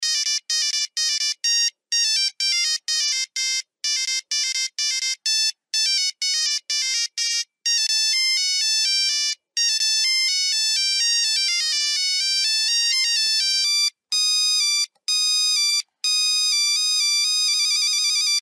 Рингтоны нокиа